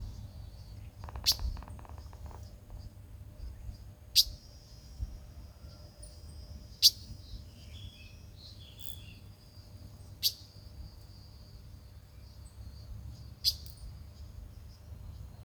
White-banded Mockingbird (Mimus triurus)
Detailed location: Lago Salto Grande
Condition: Wild
Certainty: Observed, Recorded vocal
Calandria-Real.mp3